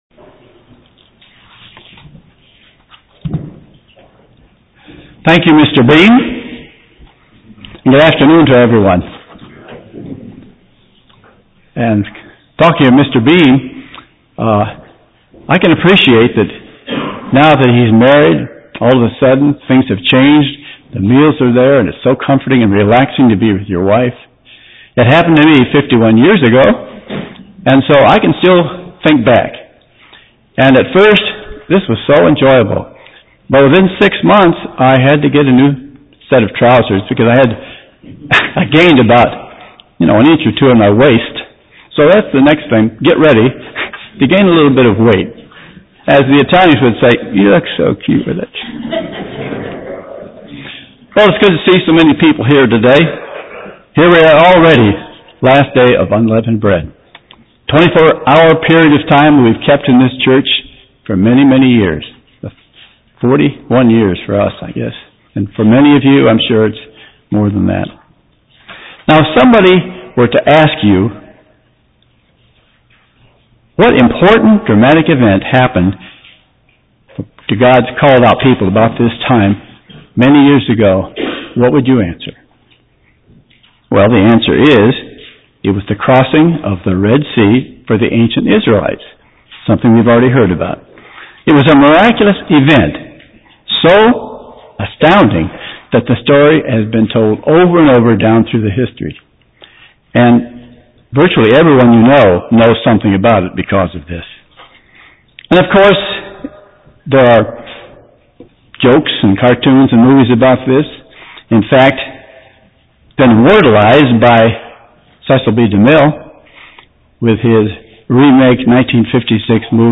UCG Sermon Studying the bible?
Given in Buford, GA